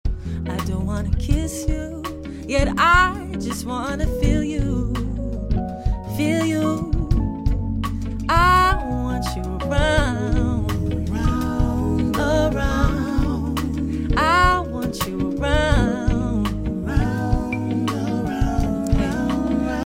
so relaxing and just hits your soul different.